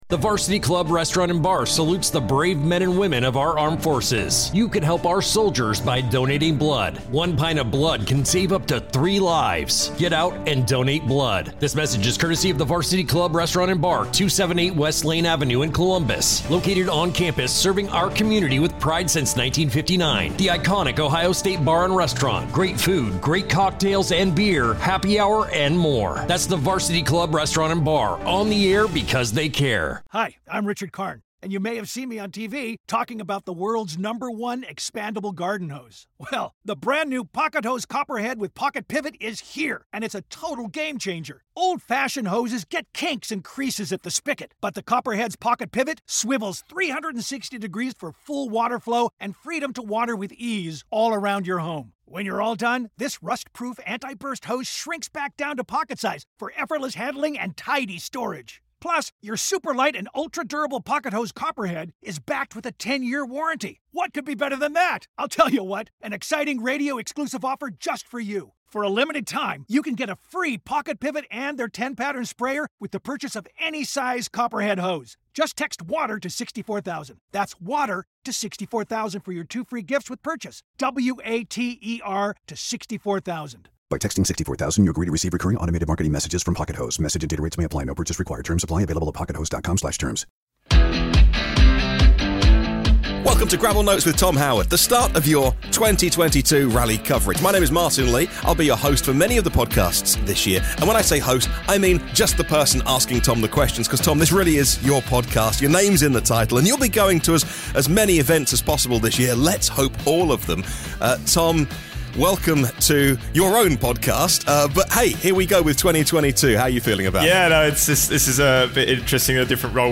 Monte Carlo Preview + Driver Interviews
We also hear from Sebastien Ogier, Craig Breen, Oliver Solberg, Ott Tanak, Kalle Rovanpera and Elfyn Evans ahead of action starting later today.